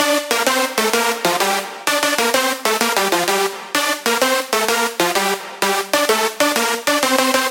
标签： 128 bpm Electronic Loops Synth Loops 1.26 MB wav Key : Unknown
声道立体声